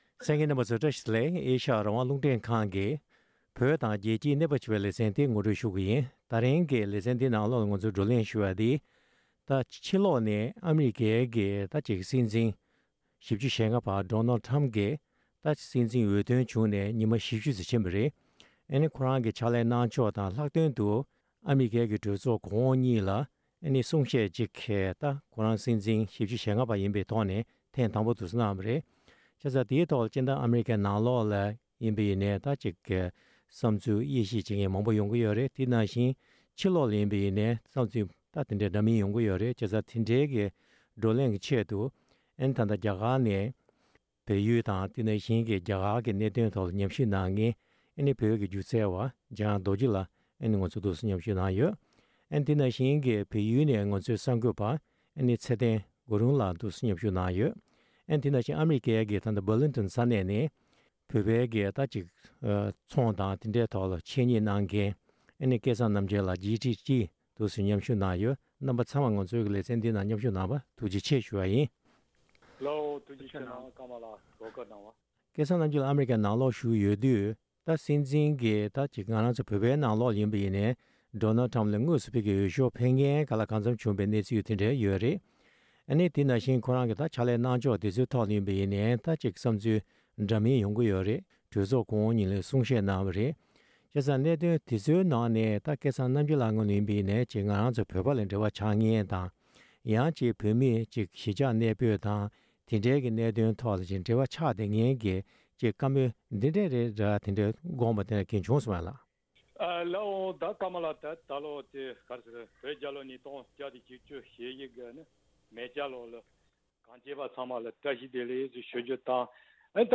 རྒྱ་གར་དང་བལ་ཡལ་གྱི་ནང་ཨ་རིའི་གཞུང་འཛིན་གསར་པའི་ཤུགས་རྐྱེན་དང་གཟིགས་ཚུལ་སྐོར་བགྲོ་གླེང་གནང་བ།